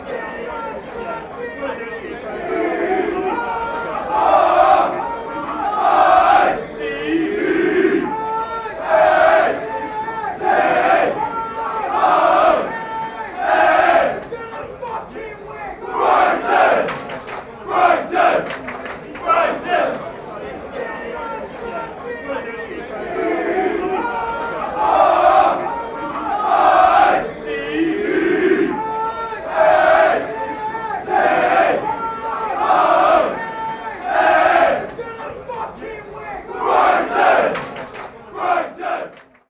A BHAFC soccer chant.